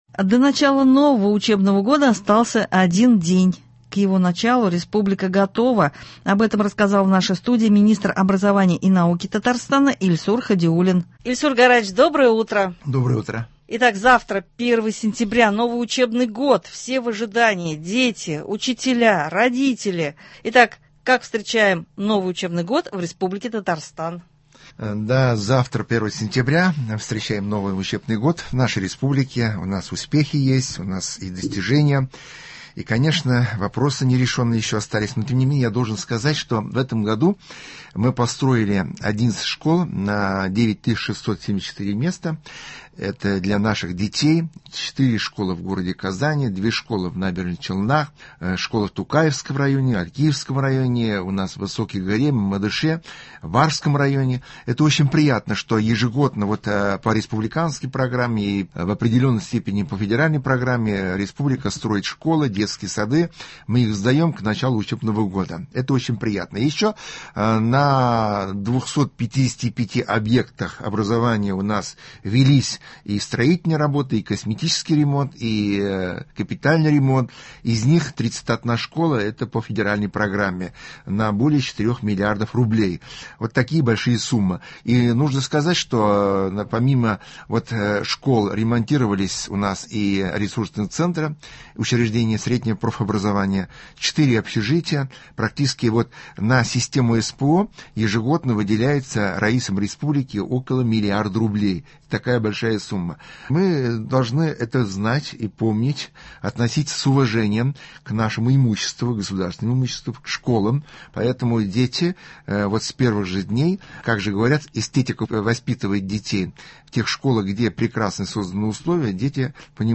Завтра начинается новый учебный год. О нем беседуем с министром образования и науки Татарстана Ильсуром Хадиуллиным, с педагогами и чиновниками системы образования.